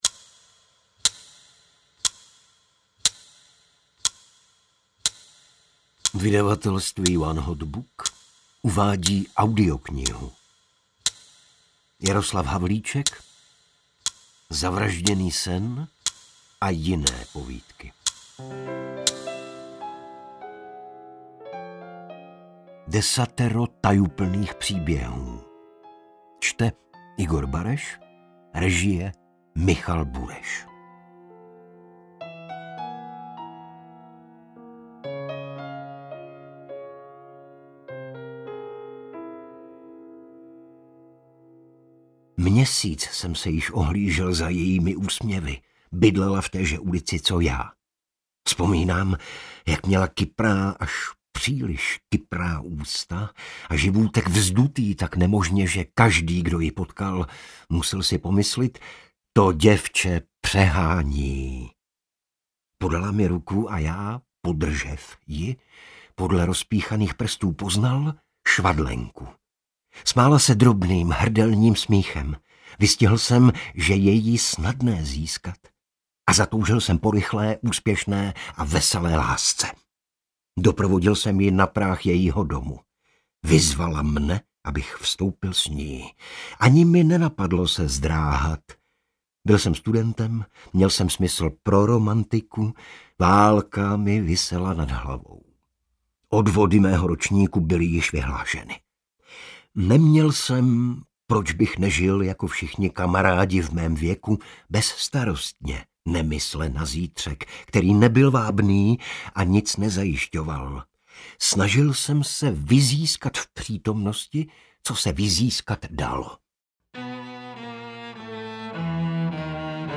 Zavražděný sen a jiné povídky audiokniha
Ukázka z knihy
• InterpretIgor Bareš